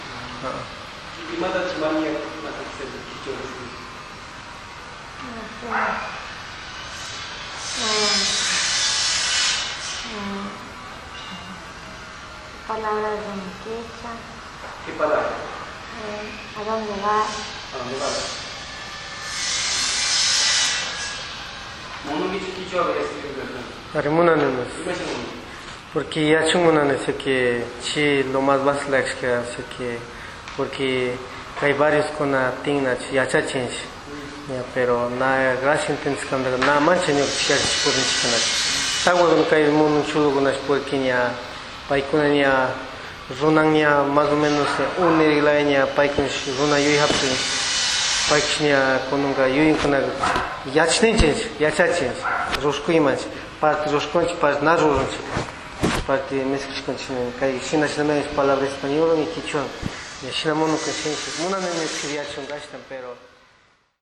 Entrevistas - San Cristóbal